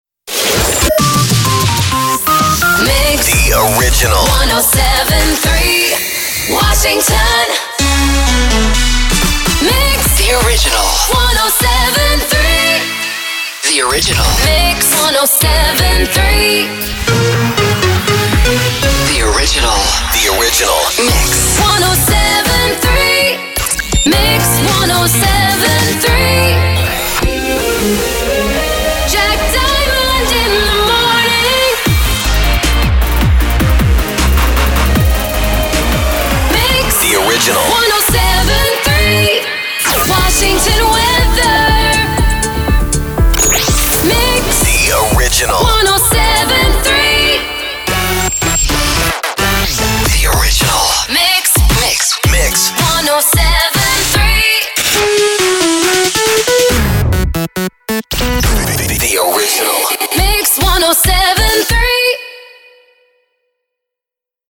blends your station voice
jingles and sweepers
can produce the package with singing only.